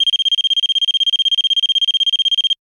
Digital Bell Ring